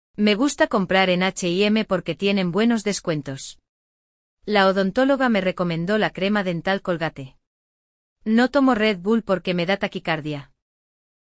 Aprenda a pronúncia correta de marcas famosas em espanhol com nosso podcast e pratique sons essenciais do idioma!